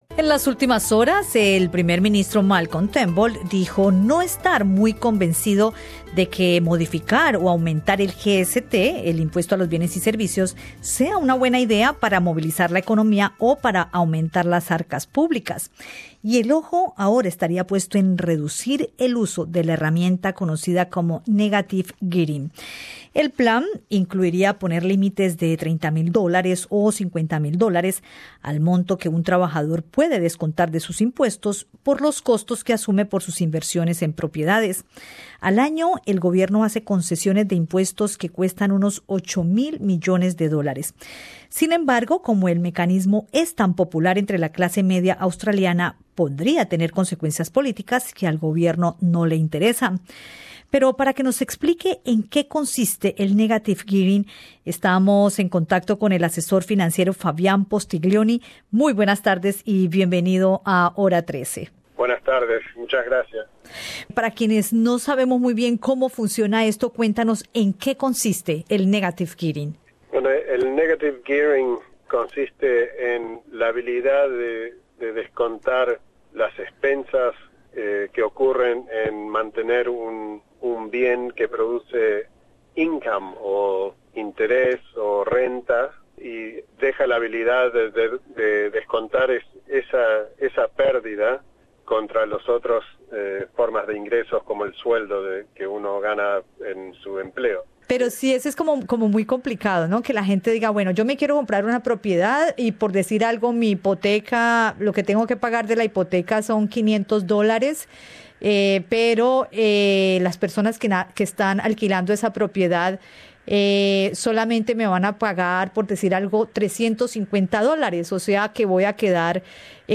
conversó con Radio SBS